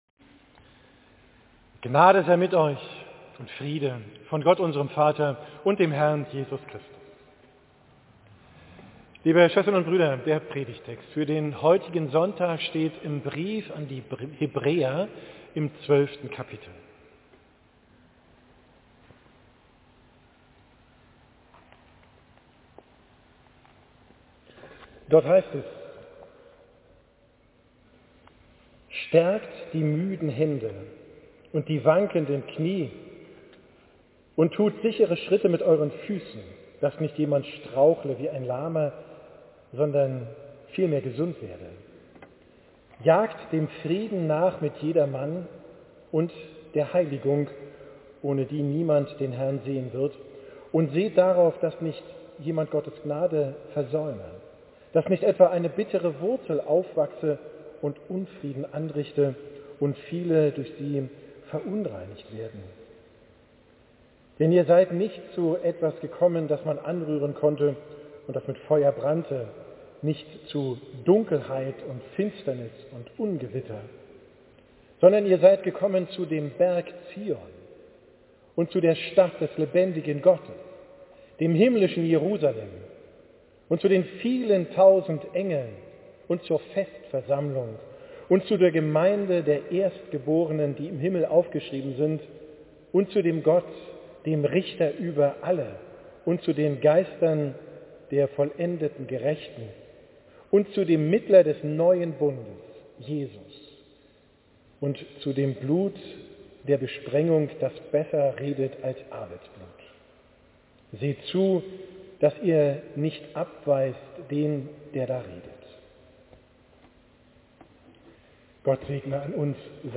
Predigt vom 2.